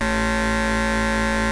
BUCHLA A2.wav